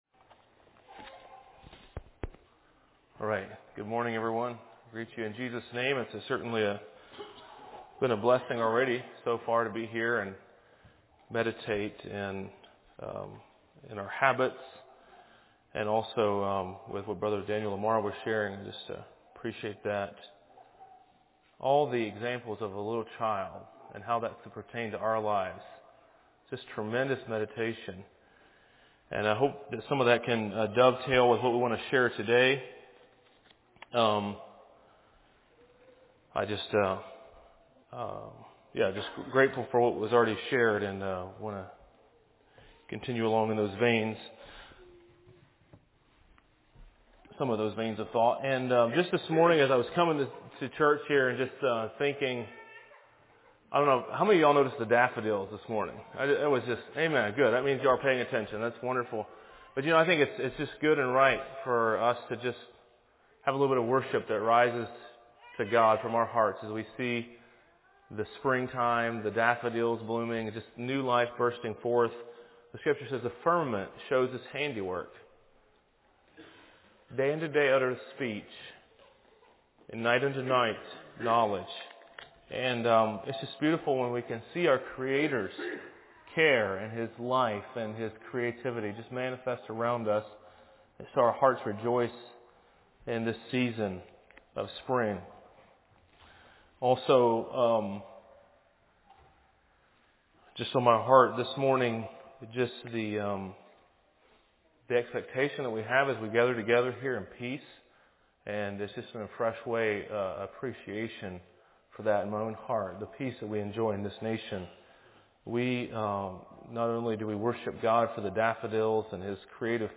A message from the series "2022 - Messages."